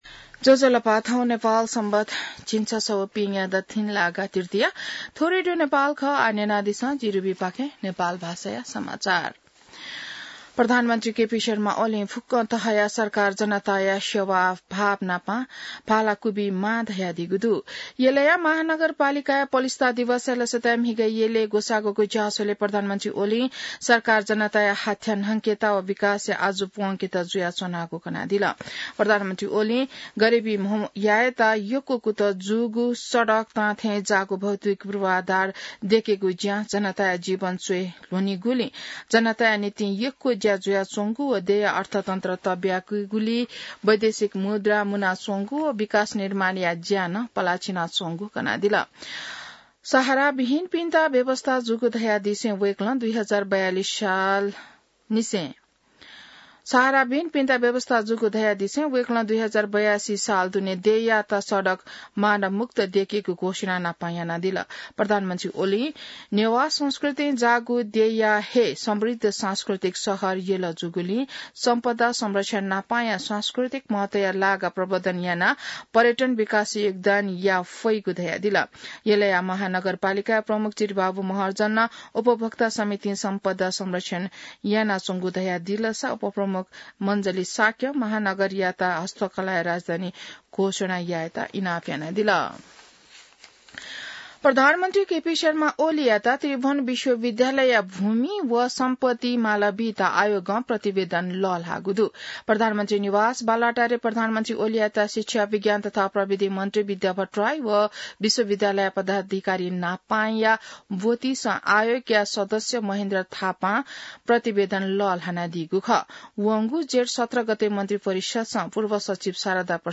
नेपाल भाषामा समाचार : ४ पुष , २०८१